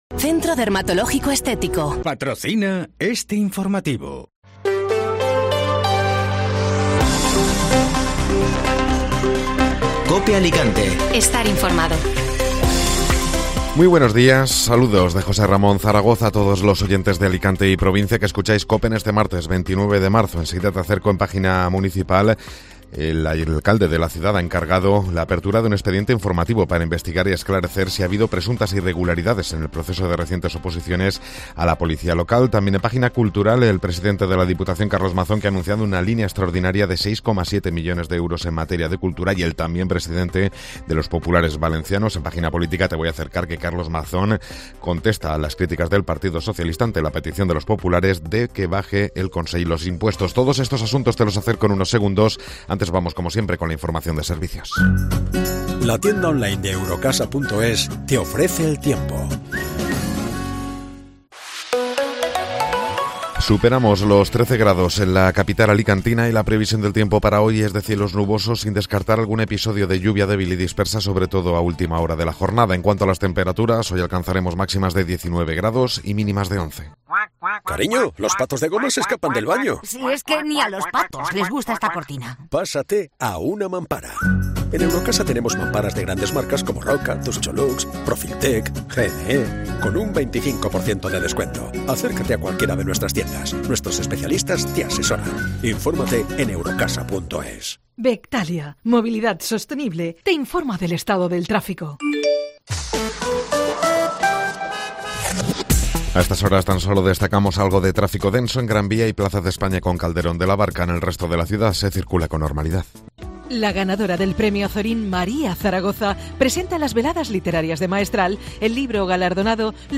Informativo Matinal (Martes 29 de Marzo)